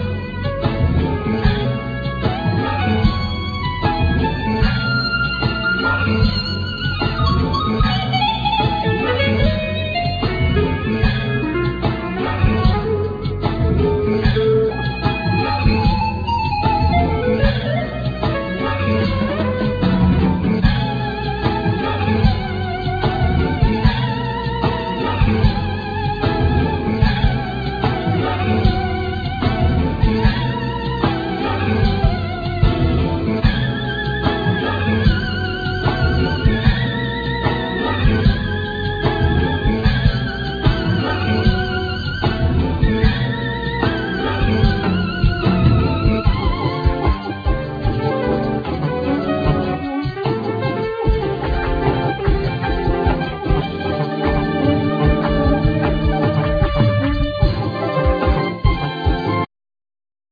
Vocal,Synthsizer,Harmonica
Drums,Keyboards
Guitar,Mandlin
Bass,Melodica